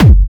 Kick 10.wav